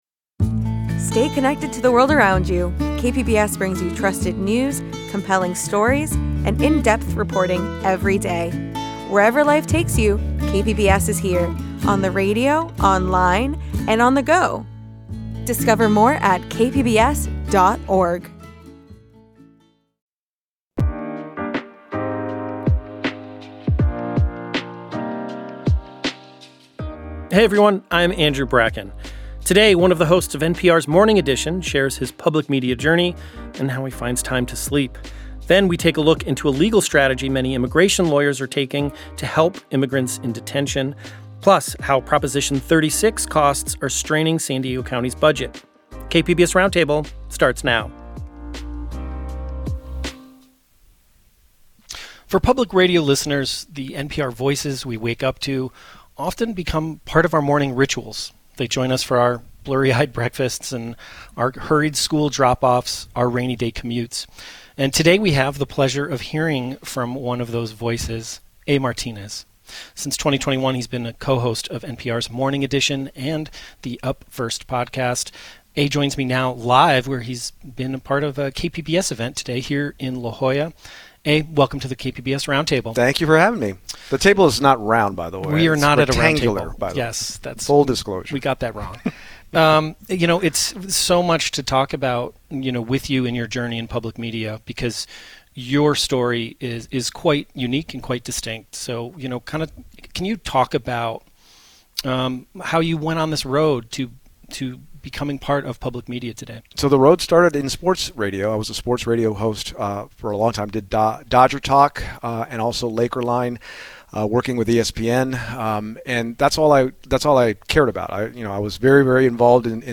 Join us for a weekly discussion with reporters, adding depth and context to the headlines driving the news in the San Diego region.